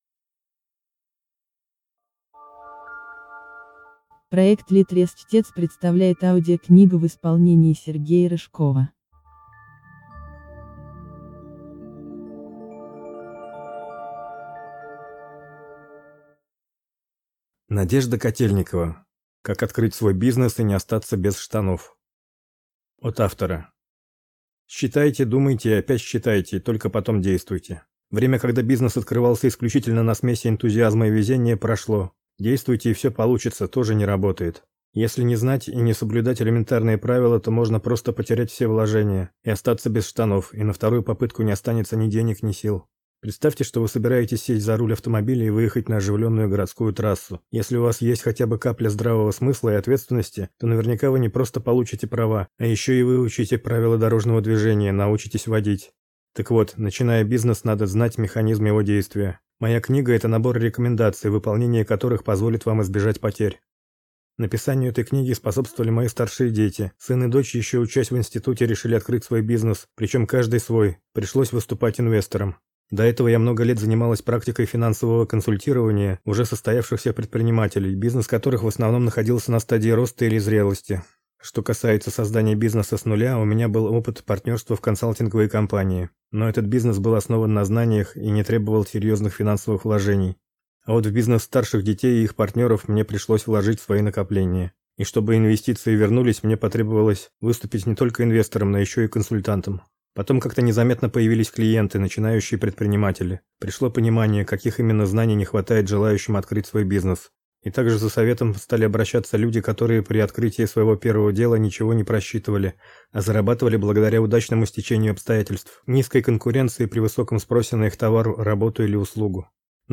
Аудиокнига Как открыть свой бизнес и не остаться без штанов | Библиотека аудиокниг